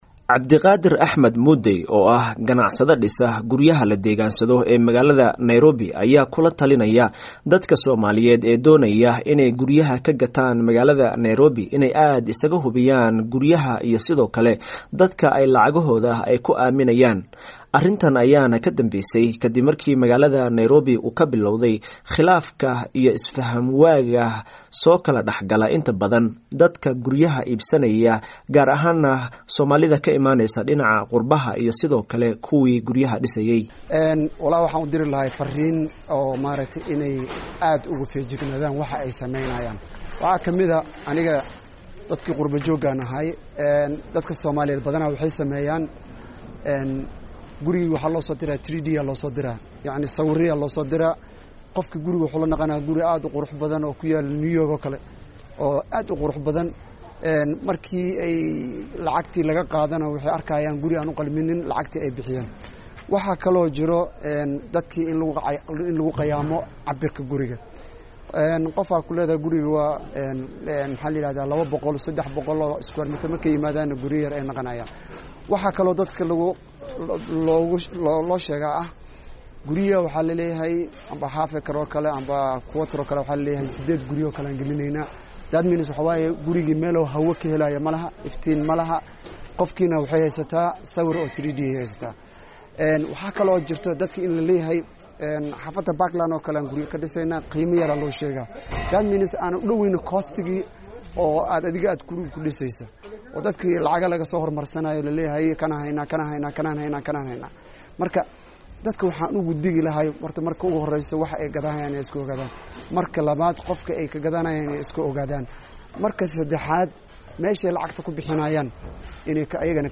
ayaa warbixintan Nairobi ka soo diray.